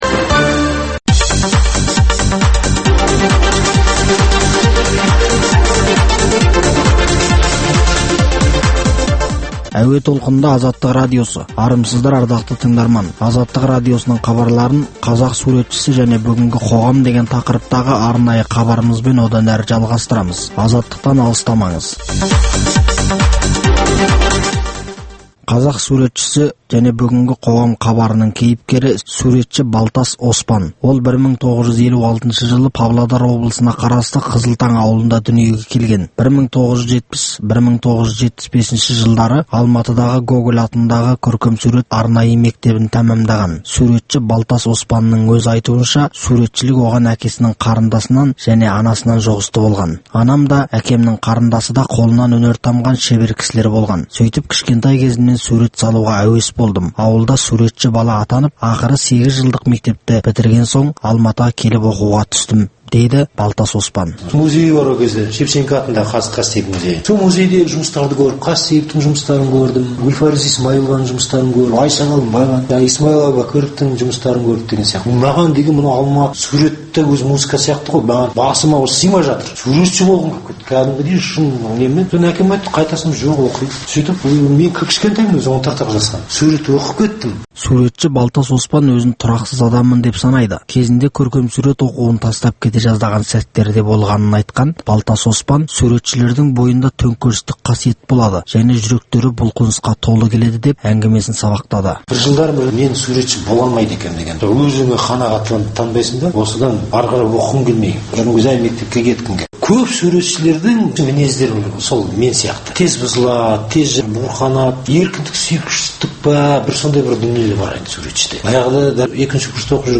Өткен тарихты зерделеу, ақтаңдақтар мен ұлт тарихындағы қиын-қыстау күндердің бүгінгі тарихта бағалануы тұрғысында тарихшы – зерттеушілермен өткізілетін сұхбат, талдау хабарлар.